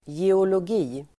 Ladda ner uttalet
Uttal: [jeolog'i:]